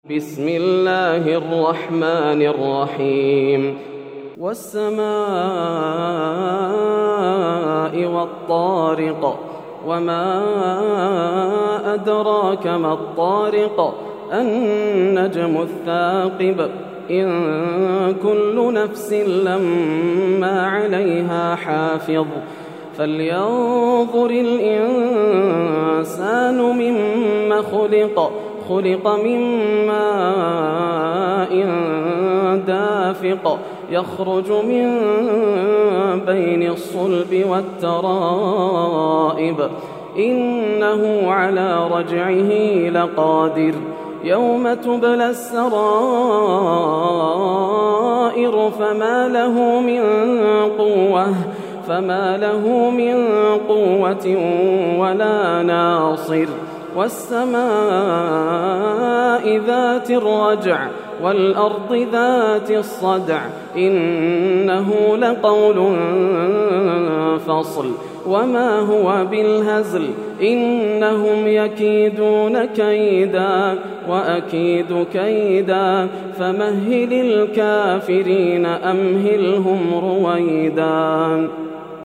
سورة الطارق > السور المكتملة > رمضان 1431هـ > التراويح - تلاوات ياسر الدوسري